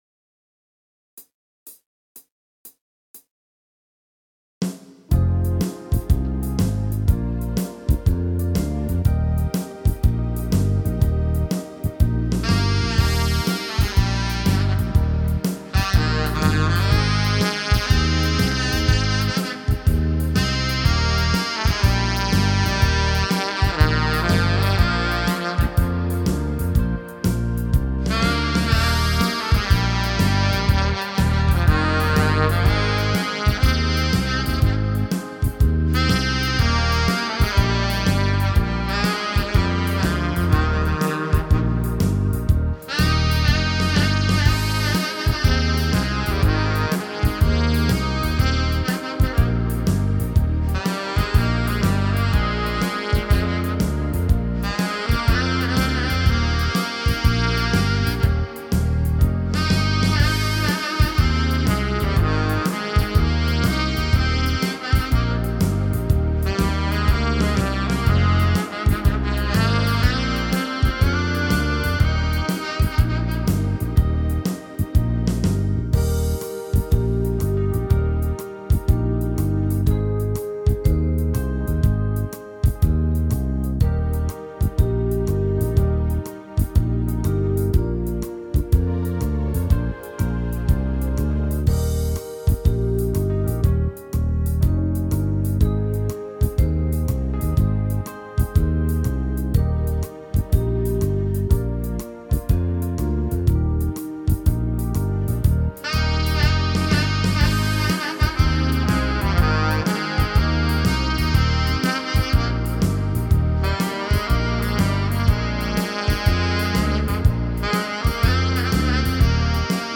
• Dansband